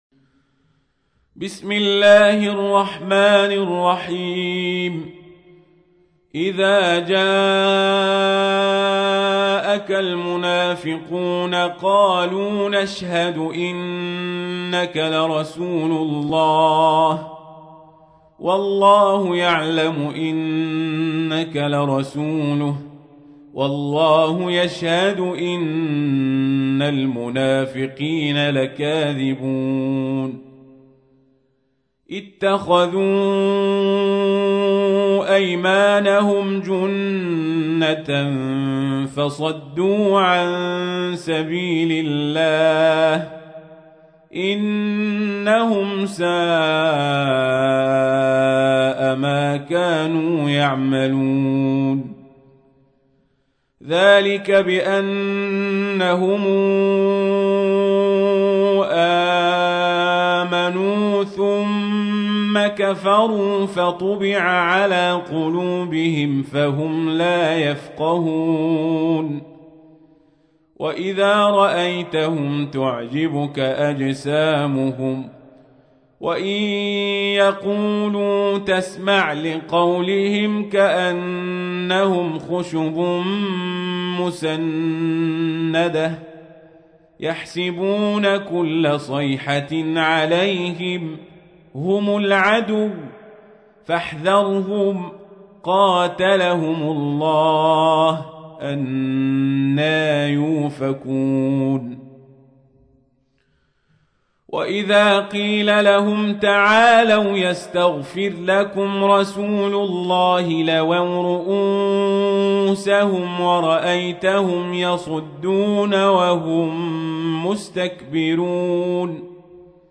تحميل : 63. سورة المنافقون / القارئ القزابري / القرآن الكريم / موقع يا حسين